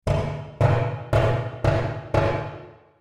На этой странице собраны звуки работающего радиатора — от мягкого потрескивания до монотонного гула.
Шум горячей воды текущей по батарее отопления